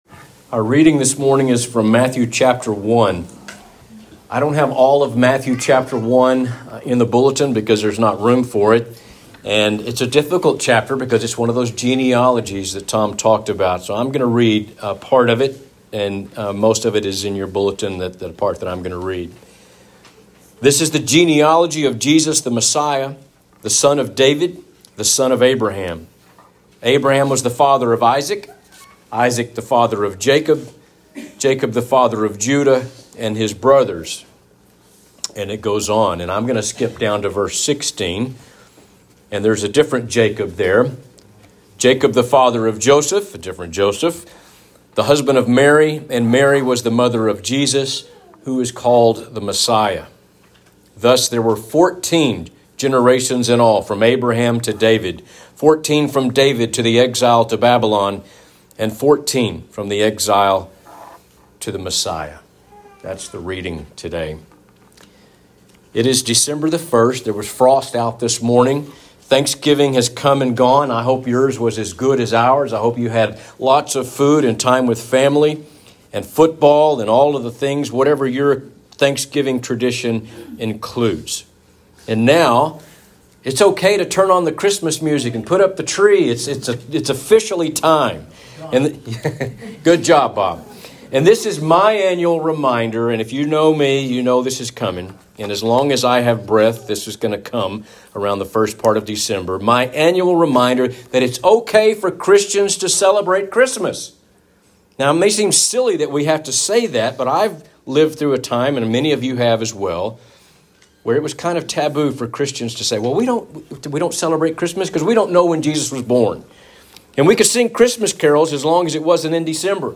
Sermons | Buffalo Gap Church of Christ